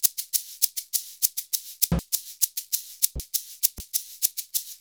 100SHAK01.wav